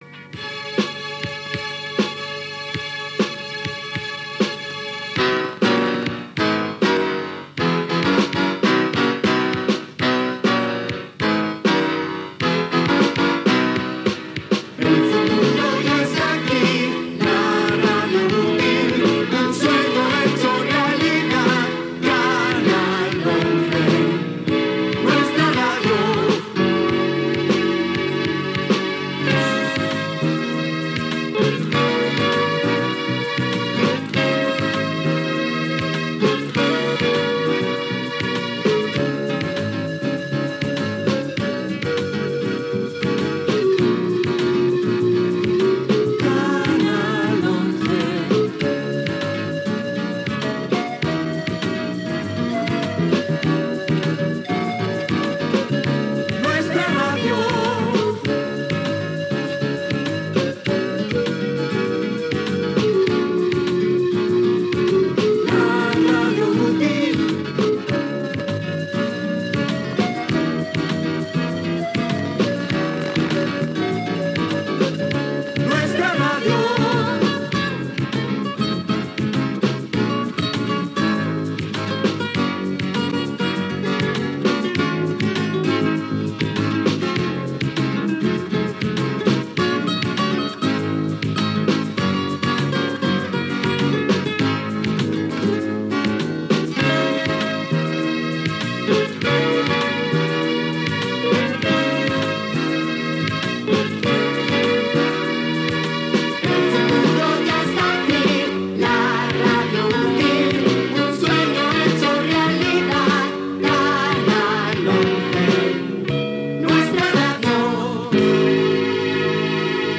Cançó identificativa del canal
Transmissió feta pel satèl·lit Hispasat